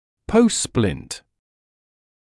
[pəust’splɪnt][поуст’сплинт]после сплинт-терапии, после ношения сплинта